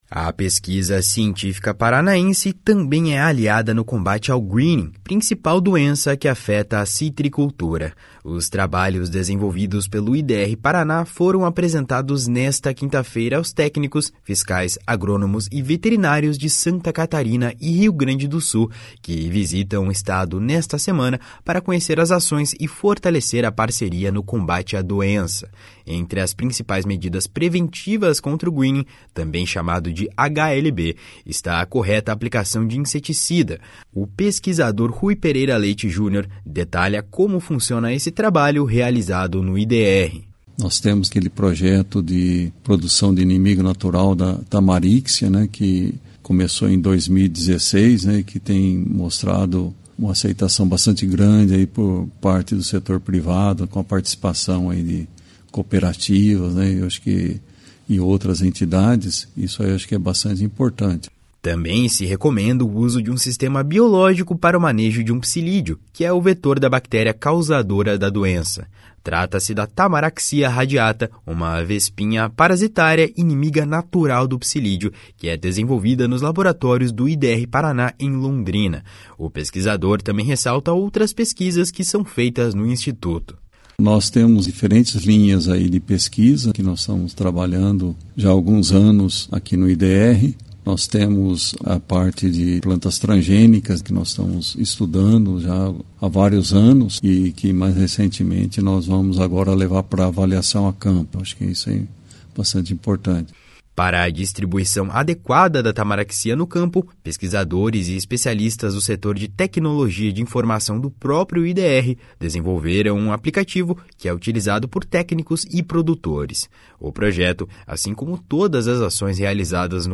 O pesquisador também ressalta outras pesquisas que são feitas no instituto.